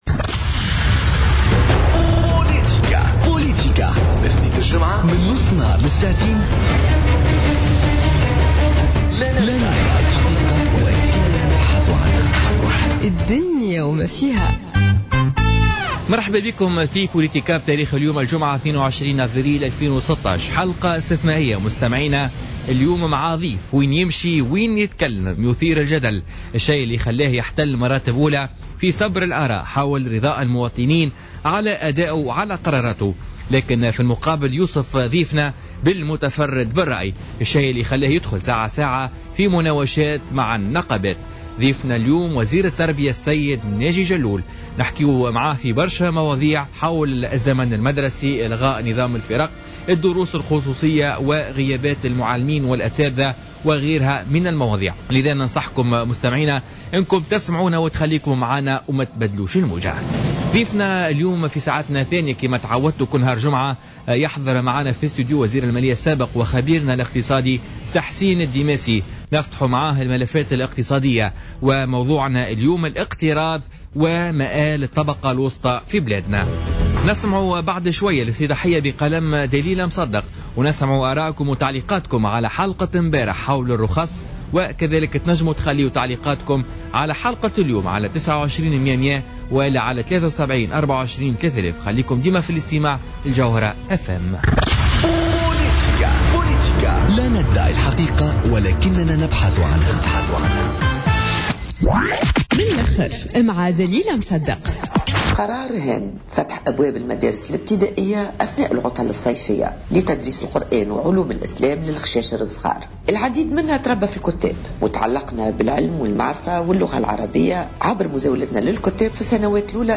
Avec Néji Jalloul, ministre de l'Education